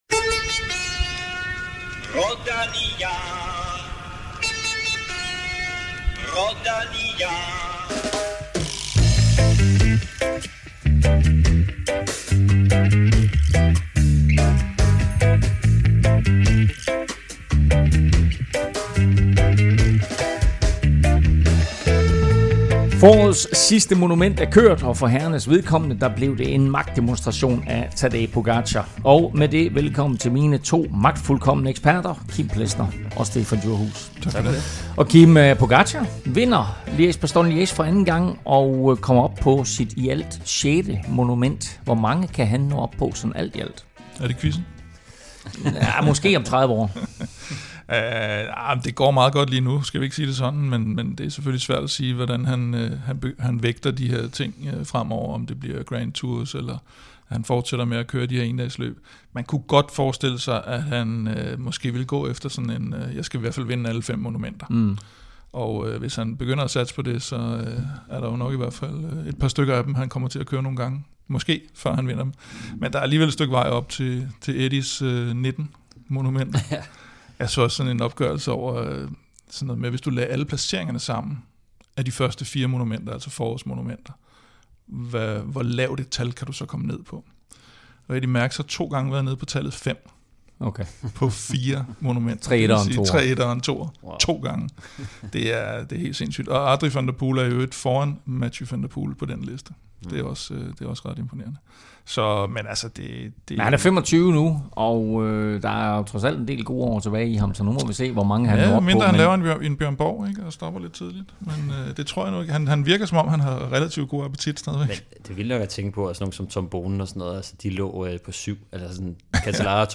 Ardenner-status med Casper P i studiet